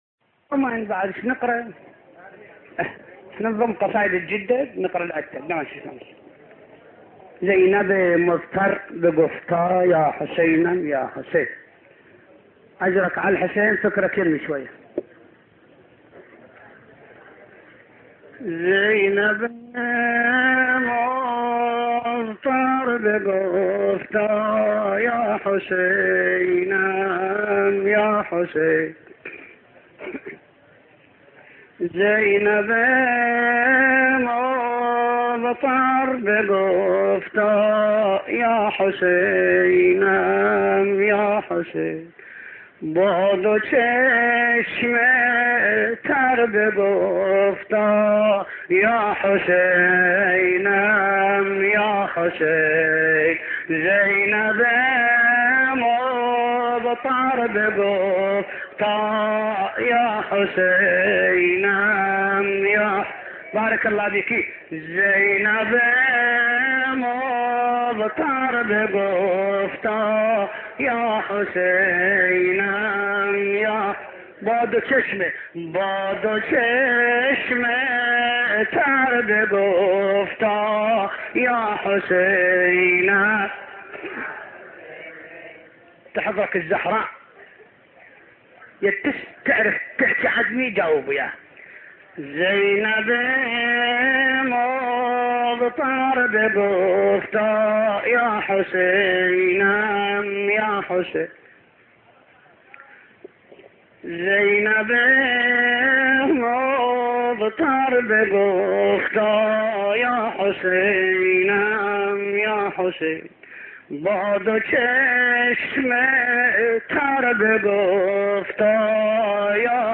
صوت | نوحه فارسی نوحه‌خوان عراقی برای حضرت زینب(س)